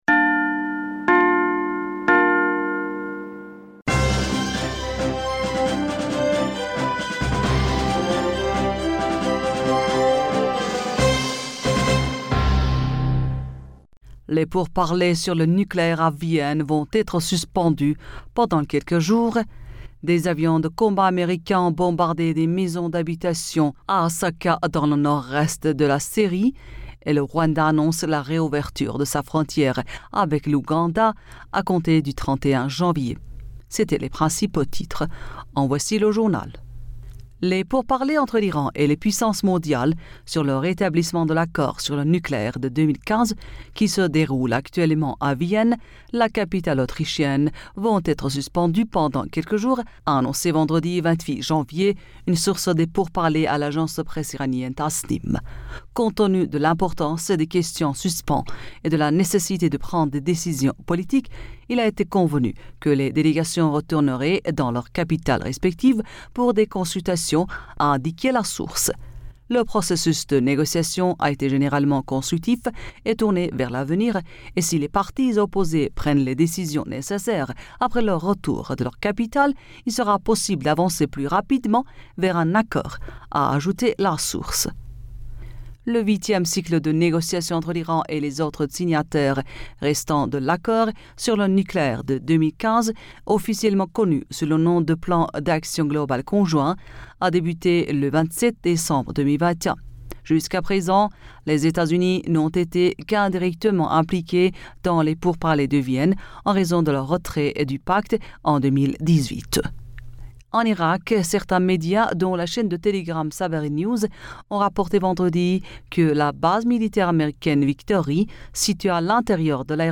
Bulletin d'information Du 29 Janvier 2022